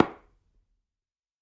Шум перемещения шахматной фигуры